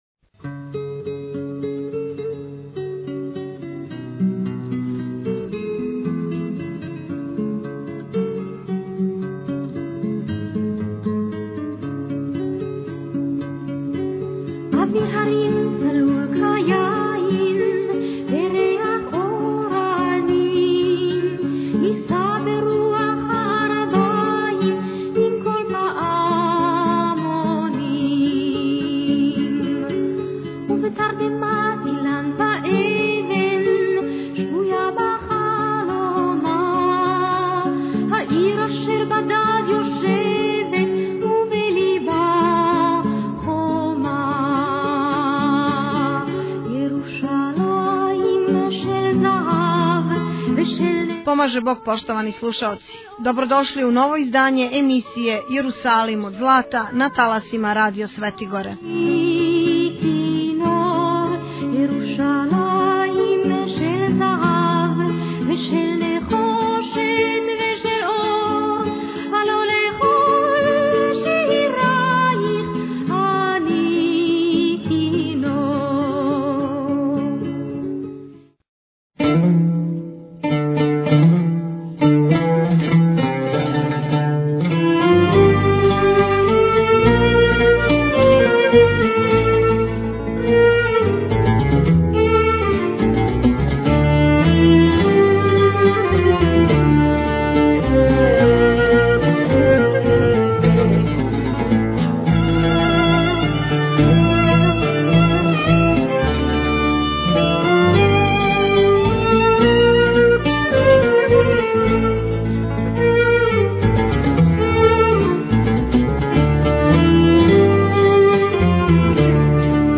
Tagged: Јерусалим од злата Наслов: Jerusalim od zlata 178 Албум: Jerusalim od zlata Година: 2014 Величина: 74:40 минута (10.7 МБ) Формат: MP3 Mono 11kHz 20Kbps (VBR) У првом дијелу емисије слушате интервју Епископа Пакрачко - славонског господина Јована за емисију "Духовни изазови" која се емитује на ХРТВ урађен након његовог устоличења, а у другом дијелу емисије доносимо информације о тренутном положају и жиивоту хришћана на Блиском истоку.